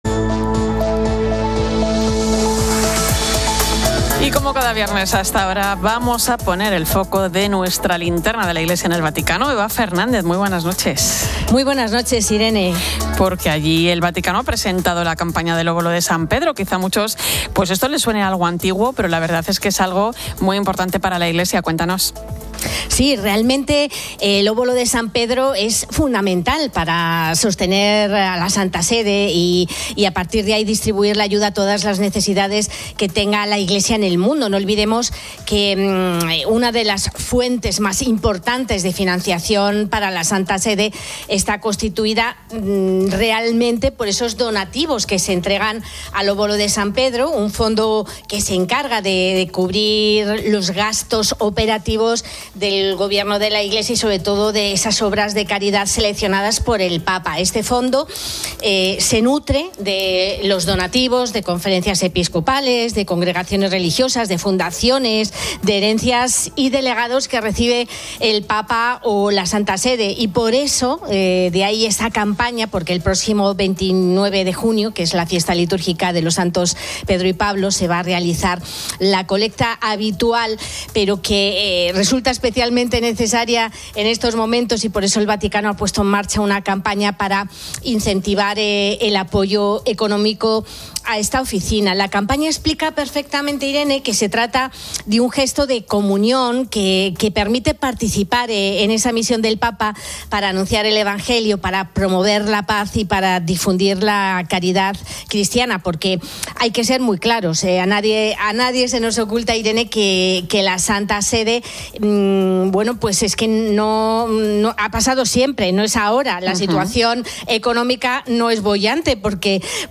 La tertulia sobre la actualidad religiosa y todos sus protagonistas.